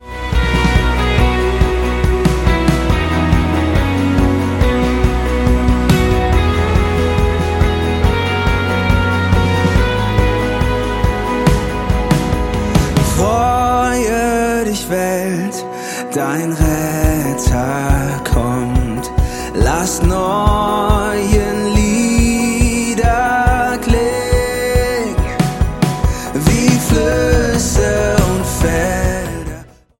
ihr erstes, lang erwartetes Studio-Album